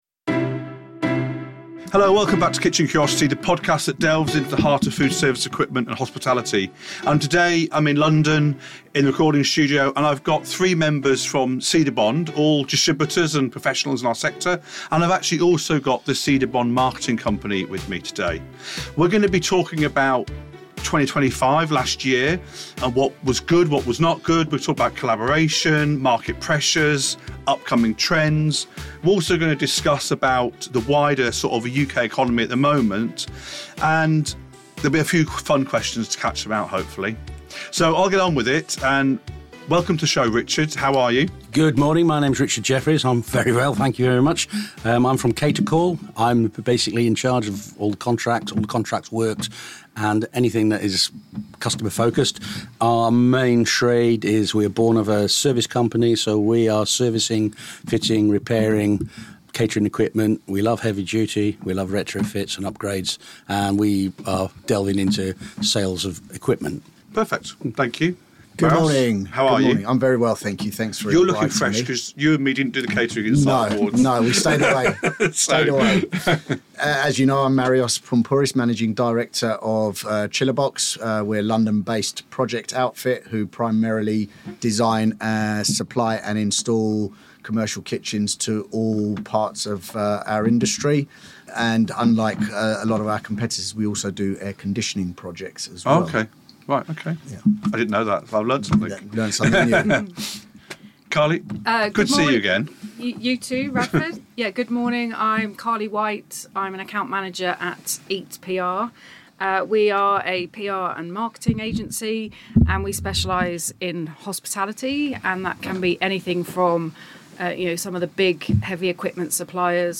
This episode comes from London with four members of Cedabond, representing some of the most respected and established distributors in the UK foodservice equipment industry. Discussion includes what’s selling, the challenges distributors face, how collaboration with consultants and manufacturers is evolving, sustainability and compliance among other topics.